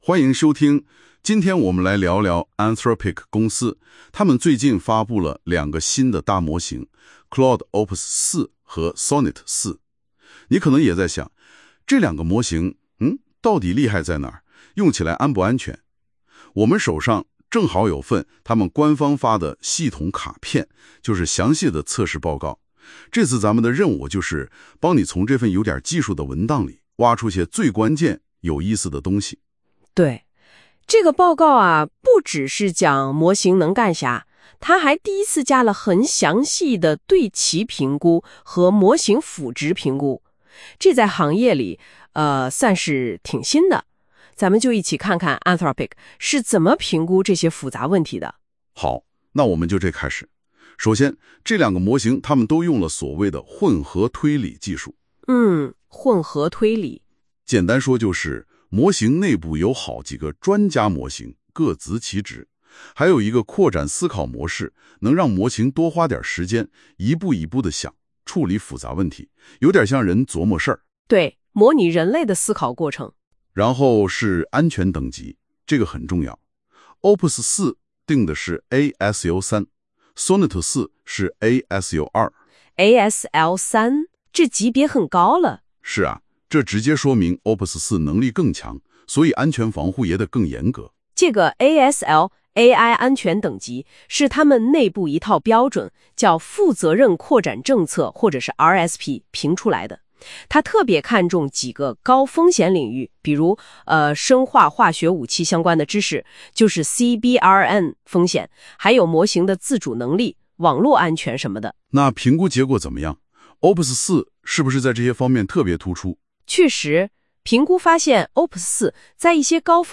听到AI讲AI是什么感觉？